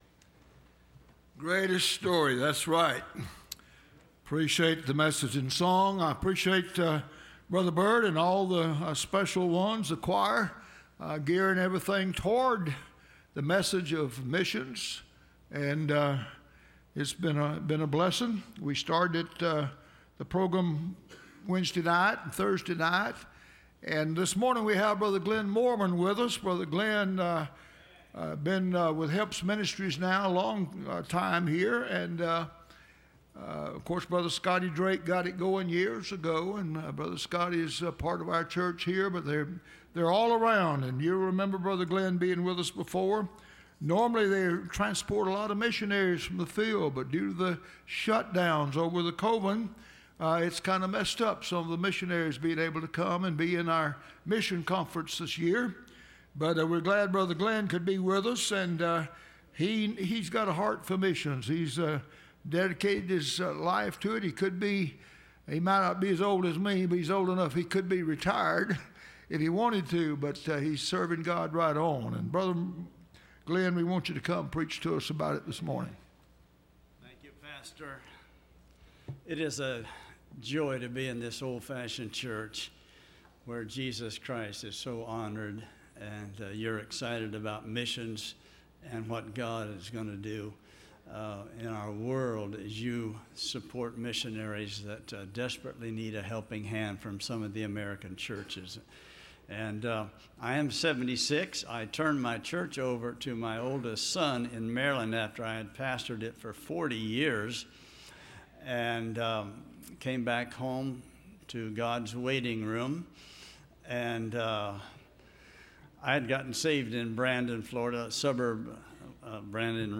The Sermon On The Child – Landmark Baptist Church
Service Type: Sunday Morning